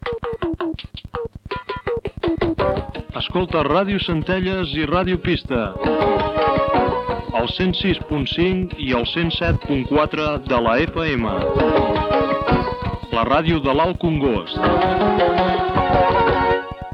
Identificació i freqüències de Ràdio Centelles i Ràdio Pista